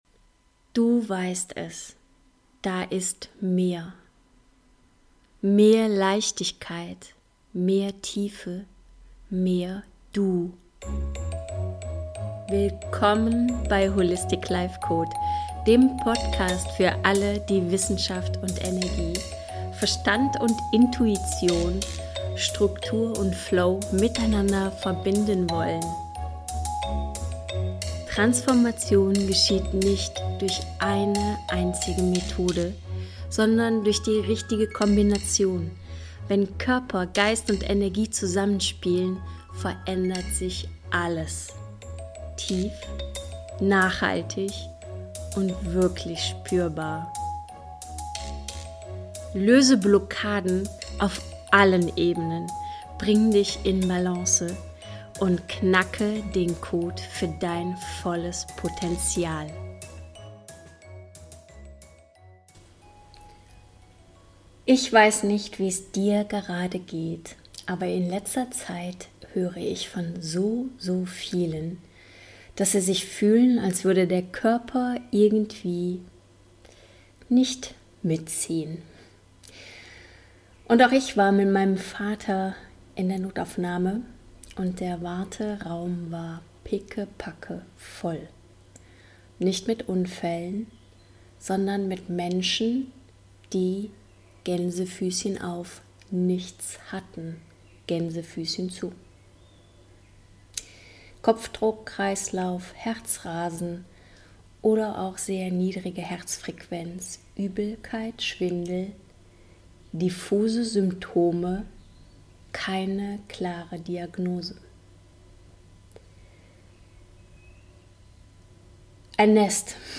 Mit einer geführten Übung, persönlicher Erfahrung aus der Notaufnahme und klaren Impulsen für mehr Selbstführung im Chaos.